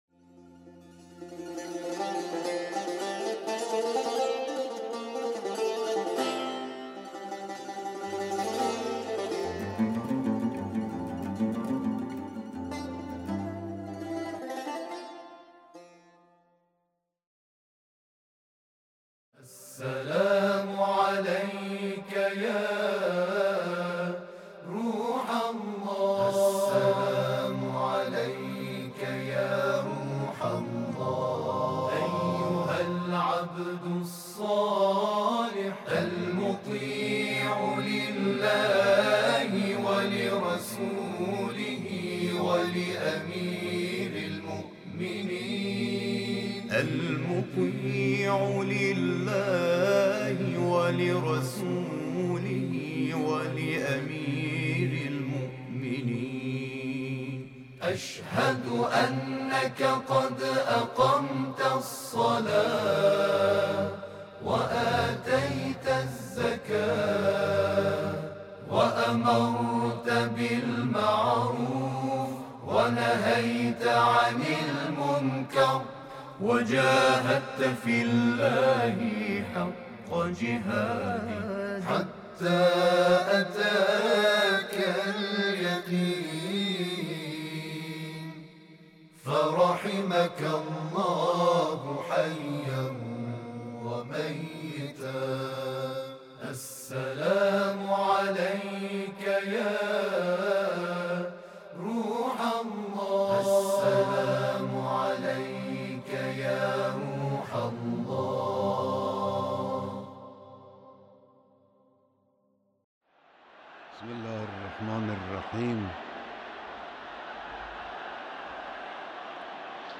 بیانات در مراسم سی‌وپنجمین سالگرد ارتحال امام خمینی(ره)